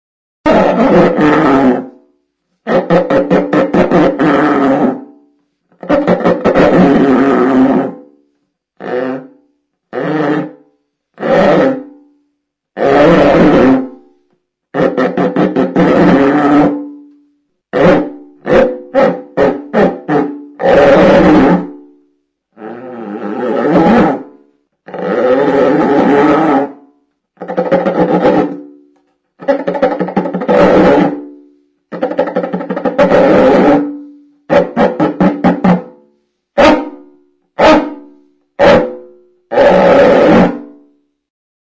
Recorded with this music instrument.
Membranophones -> Frictioned / rubbed -> Cord
Buztinezko eltze tripaduna da.